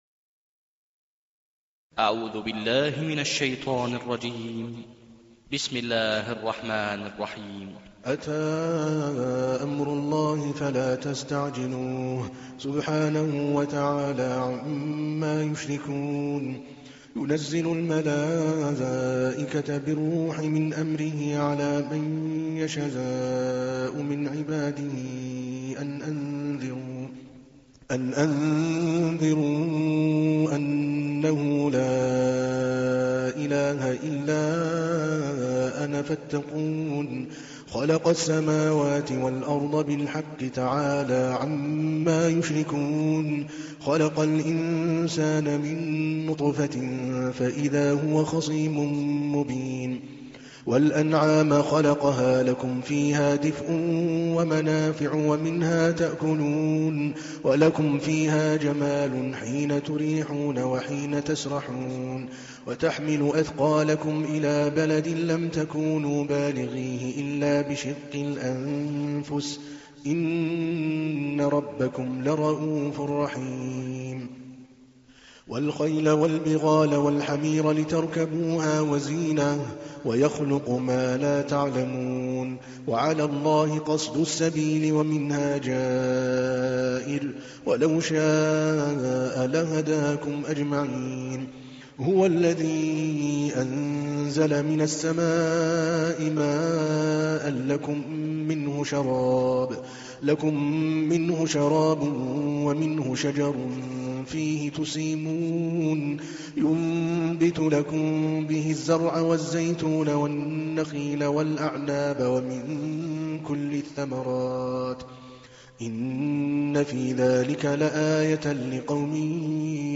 تحميل : 16. سورة النحل / القارئ عادل الكلباني / القرآن الكريم / موقع يا حسين